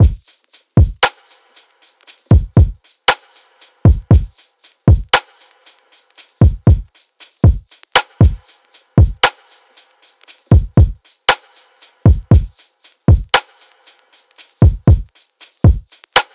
Launch (117 BPM – Ab)